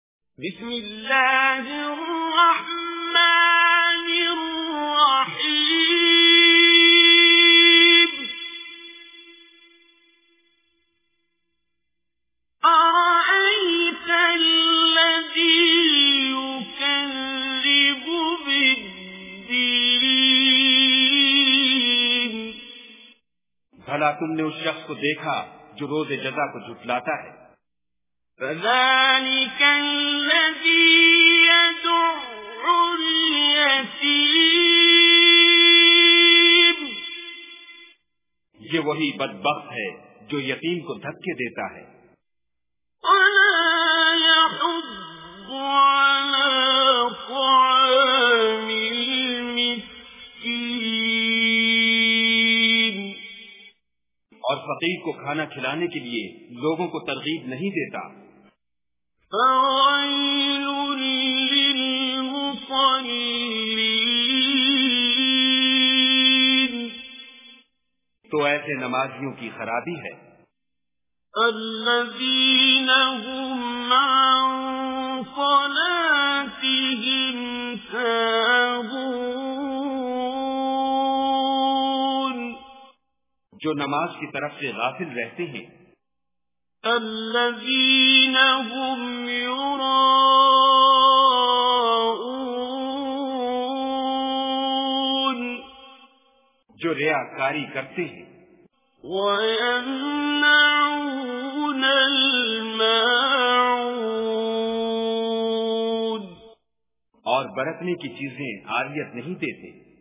Surah Maun Recitation with Urdu Translation
Surah Maun is 107th chapter of Holy Quran. Listen online and download mp3 tilawat / recitation of Holy Quran in the voice of Qari Abdul Basit As Samad.